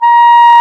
WND CLAR A#5.wav